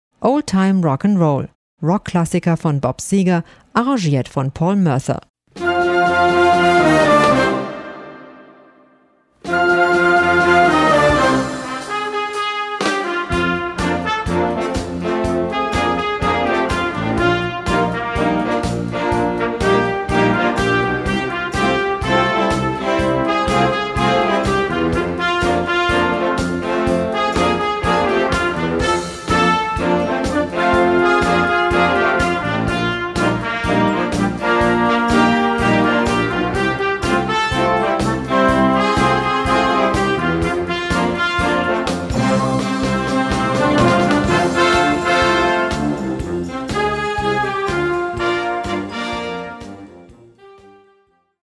Gattung: Moderner Einzeltitel Jugendblasorchester
Besetzung: Blasorchester